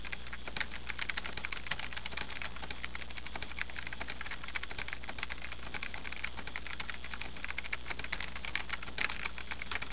Typewriter 6 Sound Effect Free Download
Typewriter 6